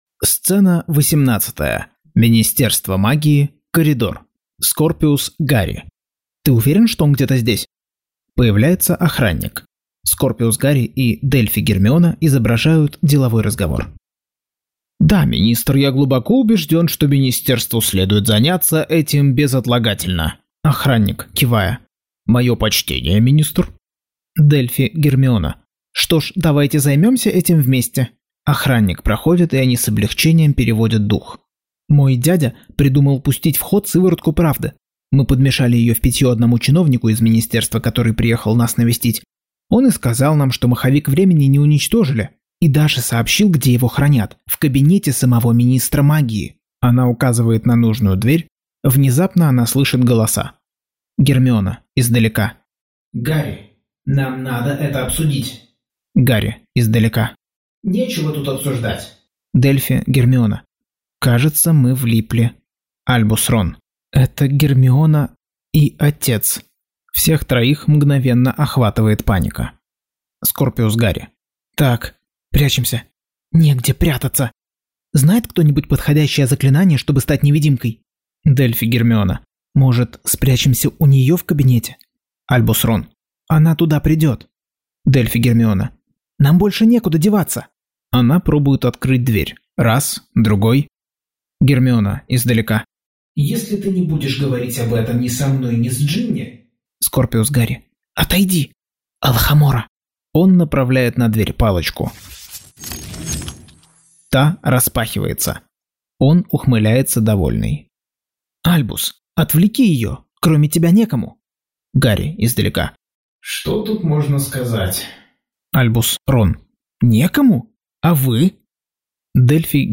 Аудиокнига Гарри Поттер и проклятое дитя. Часть 13.